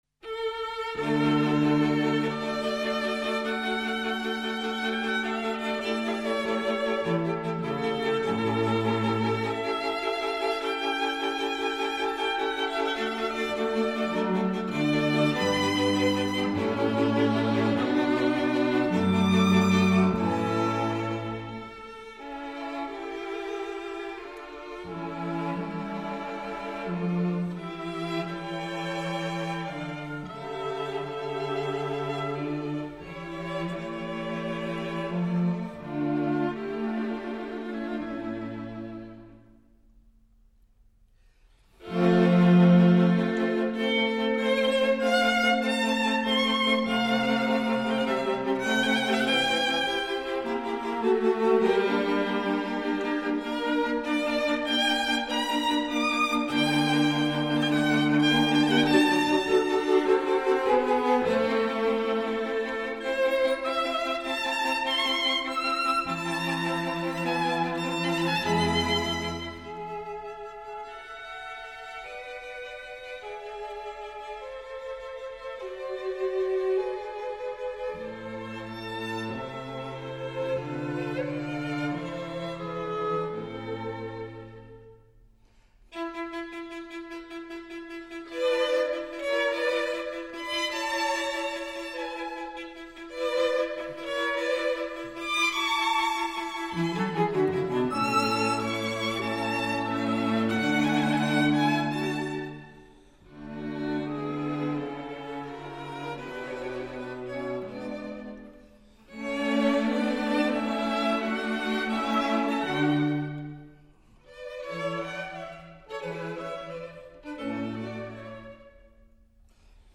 String Quartet in A major
Andante